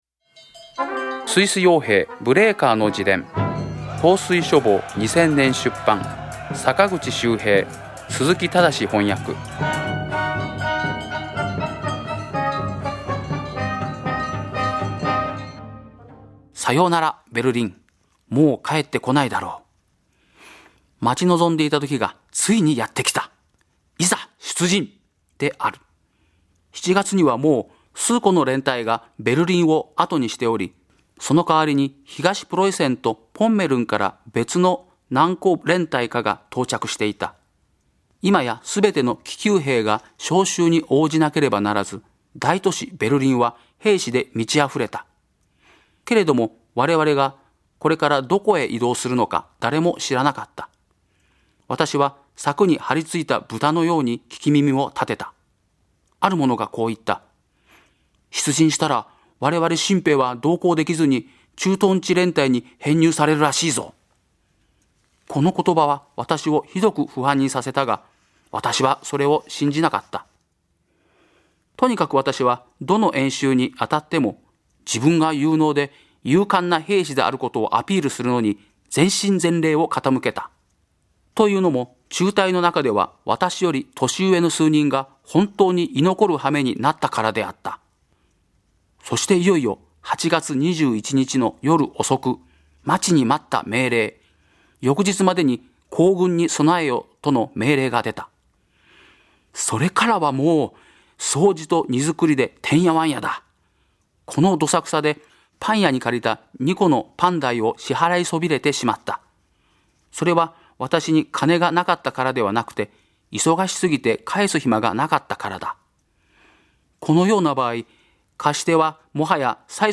朗読『スイス傭兵ブレーカーの自伝』第54回